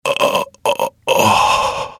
vs_fScarabx_dead.wav